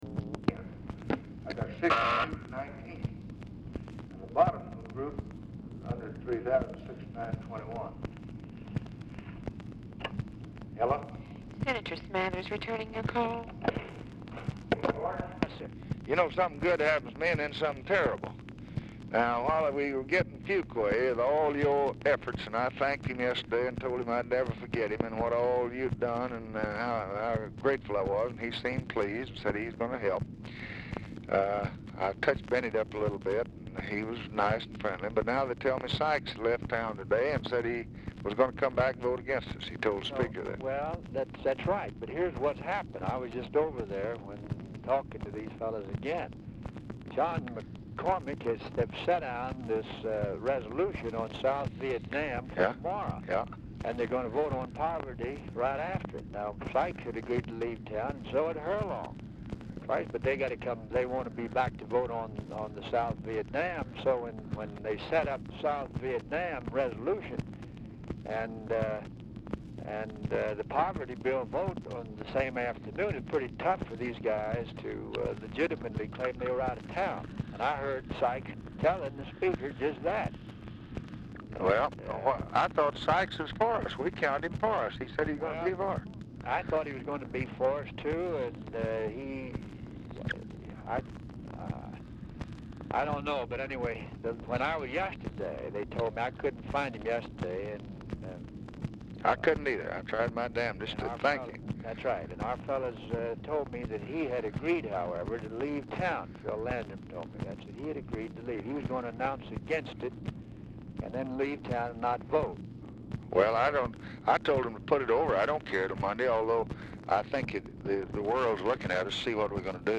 Telephone conversation # 4782, sound recording, LBJ and GEORGE SMATHERS, 8/6/1964, 2:29PM | Discover LBJ
BRIEF OFFICE CONVERSATION PRECEDES CALL
Format Dictation belt
Location Of Speaker 1 Oval Office or unknown location
Specific Item Type Telephone conversation